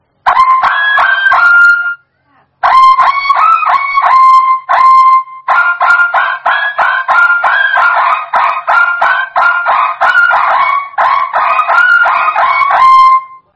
Nada dering Rem Truk Sulawesi viral TikTok
nada-dering-rem-truk-sulawesi-viral-tiktok-id-www_tiengdong_com.mp3